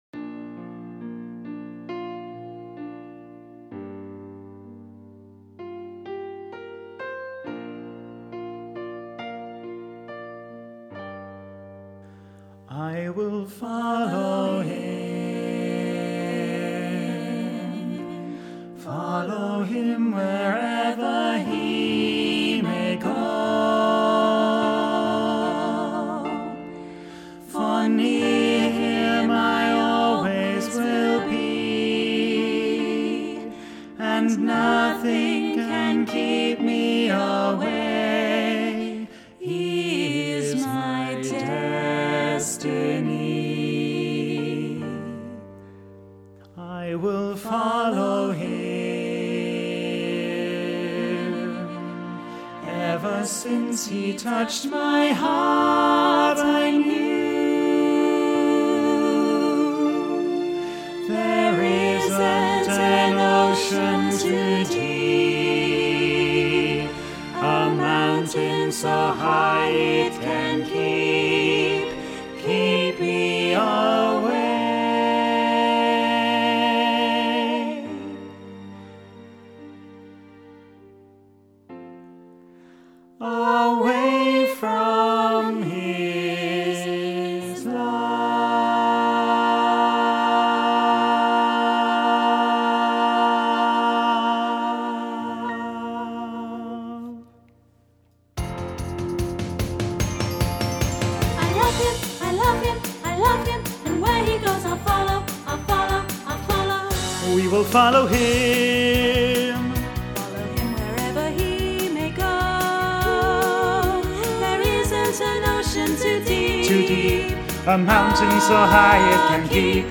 Listen to bass track with soprano and alto accompaniment